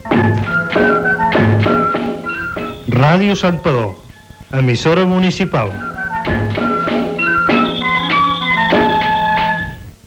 Indicatiu de l'emissora
Banda FM